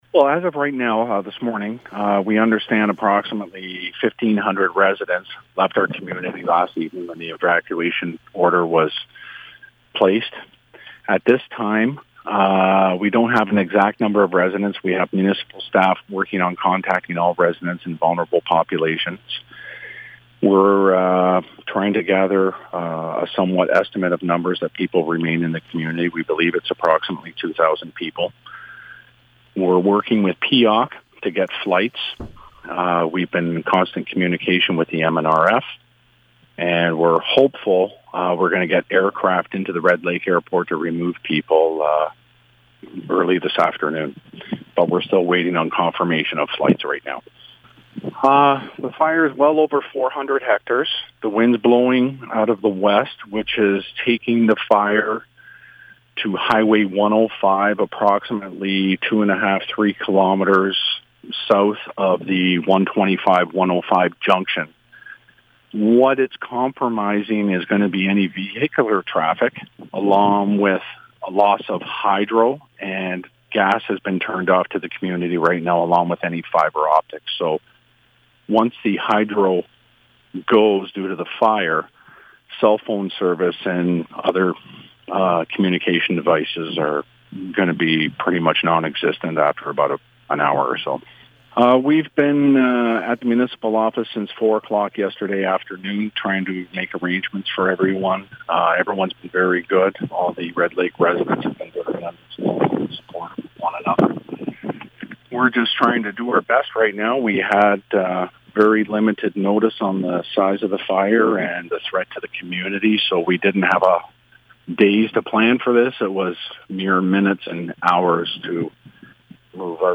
To hear more from Mayor Fred Mota, visit the Audio link below.